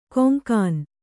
♪ koŋkān